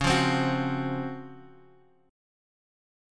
C#M11 chord